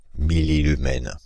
Ääntäminen
France (Île-de-France): IPA: /mi.li.ly.mɛn/